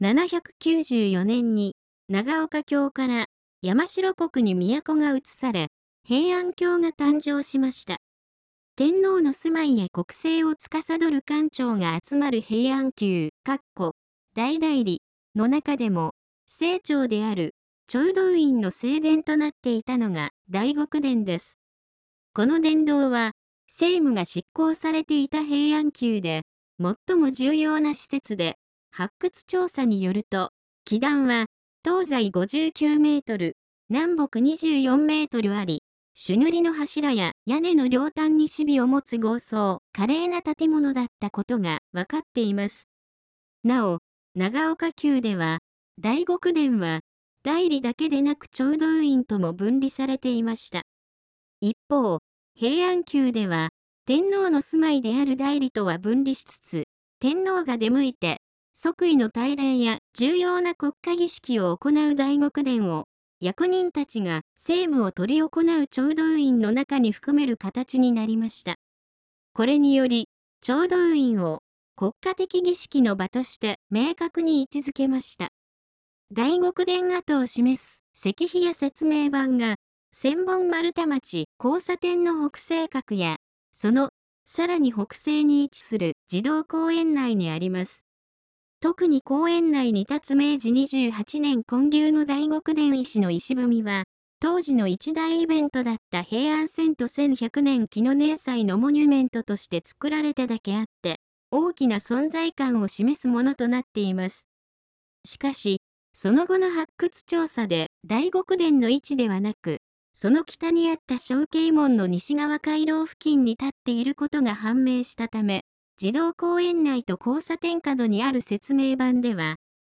読み上げ音声